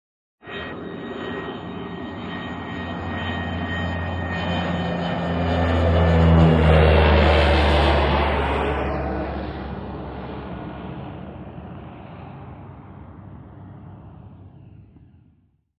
AIRCRAFT PROP TWIN TURBO: EXT: Fly by medium speed.